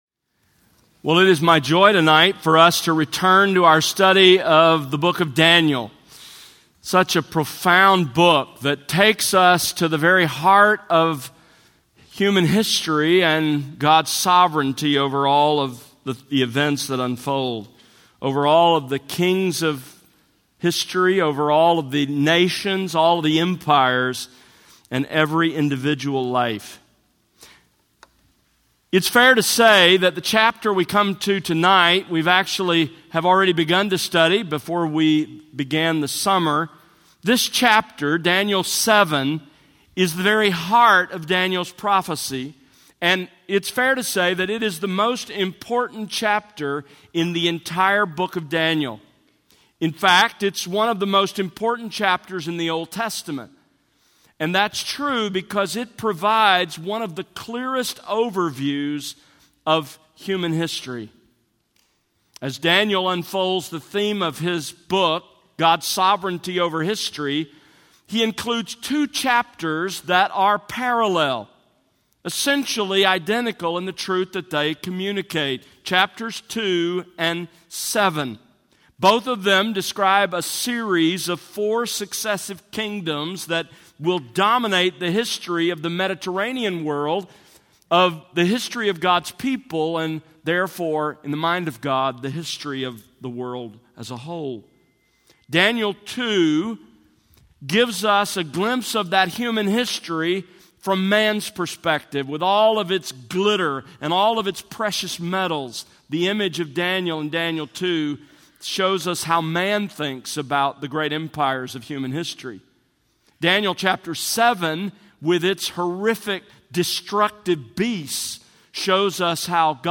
Sermons That Exalt Christ